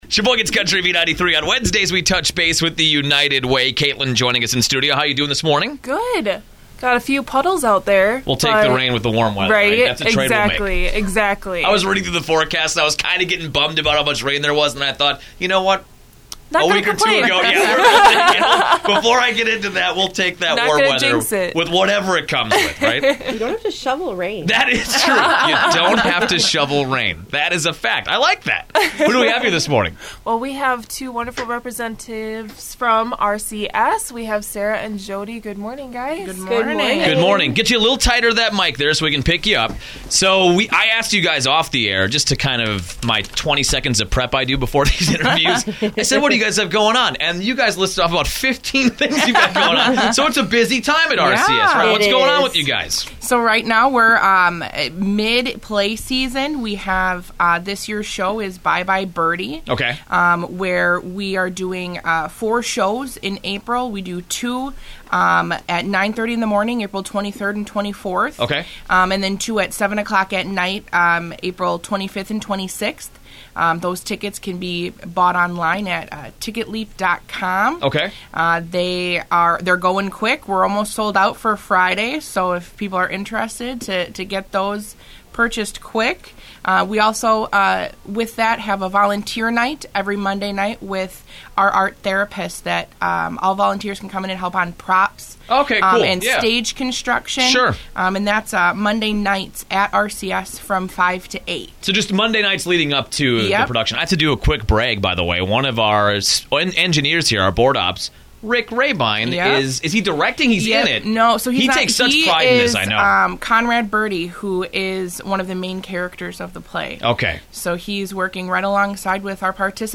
RCS Empowers - Radio Spot